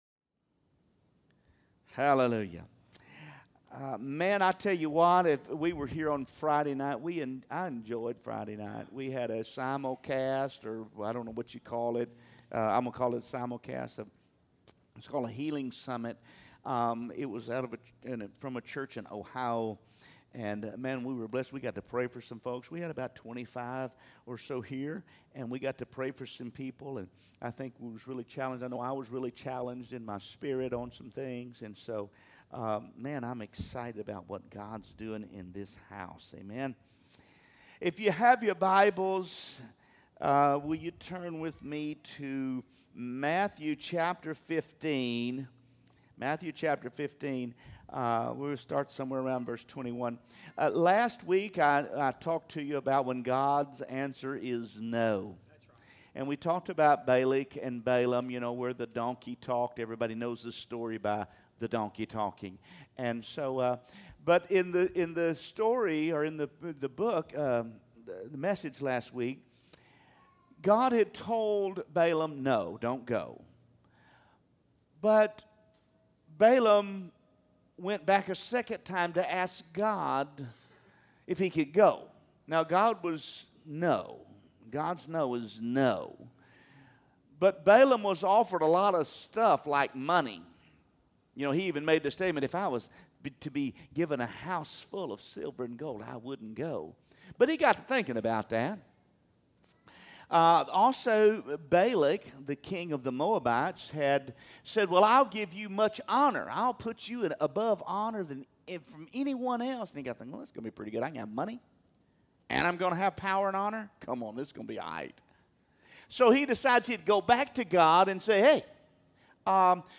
A message from the series "Sunday Message."